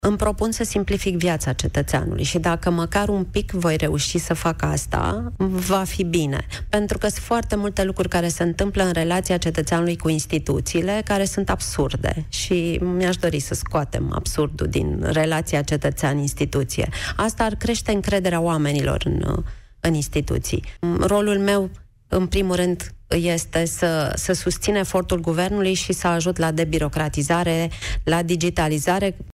Oana Gheorghiu, proaspătul vicepremier al României, spune că își doreștte, prin noua funcție, să scoată absurdul din relația cetățean – instituțiile statului. Prezentă la Piața Victoriei de la Europa FM, Gheorghiu susține că își propune să simplifice viața cetățeanului.